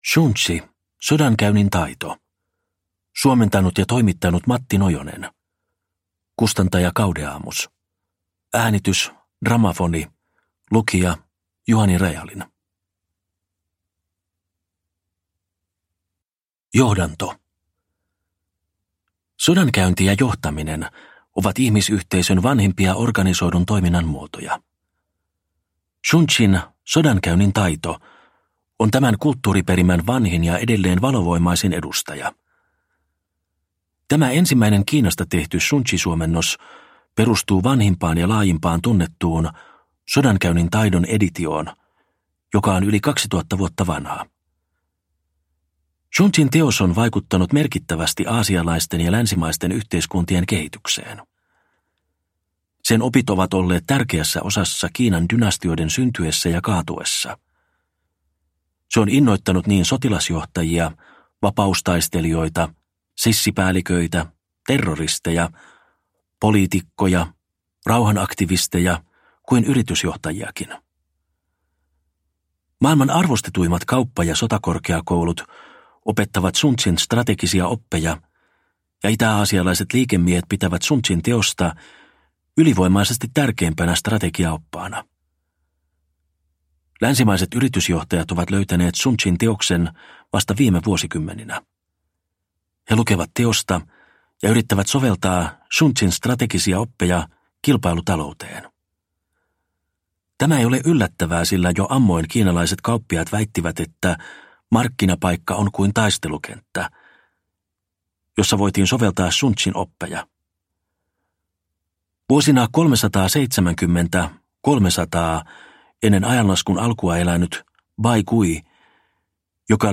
Sodankäynnin taito – Ljudbok – Laddas ner